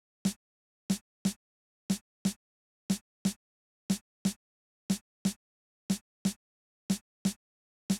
29 Snare 2.wav